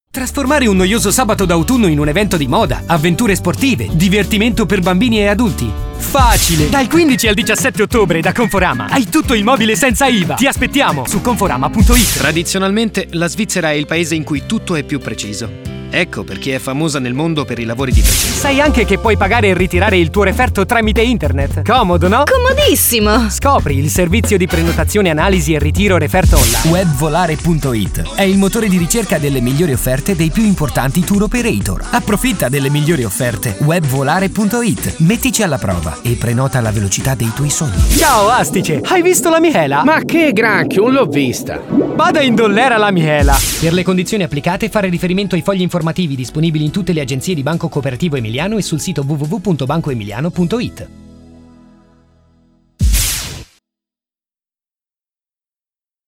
Ho una voce calda e giovane, utilizzabile in ogni tipo di progetto.
Sprechprobe: Werbung (Muttersprache):